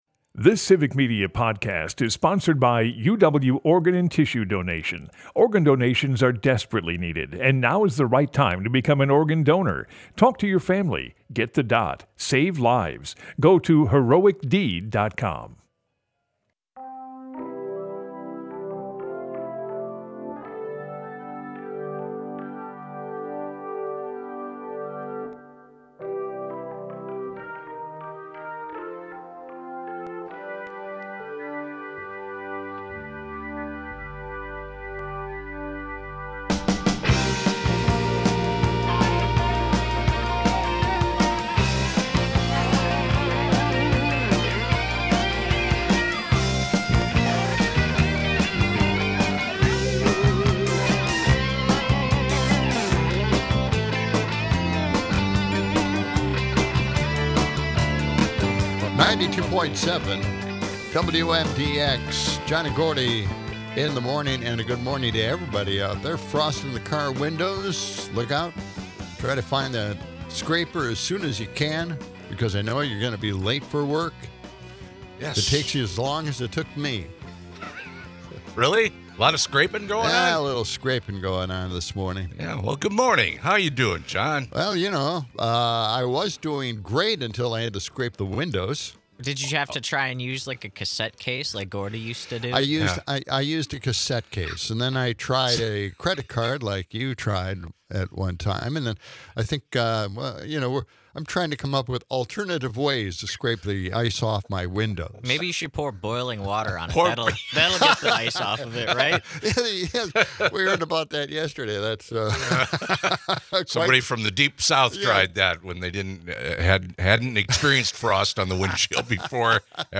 Broadcasts live 6 - 8am weekdays in Madison.
In Idiocracy, we share a laugh about the movement to ban bubble baths for men, in an effort to get rid of work from home. Closing out, the guys chat with callers on privatizing social security, bubble baths, and more!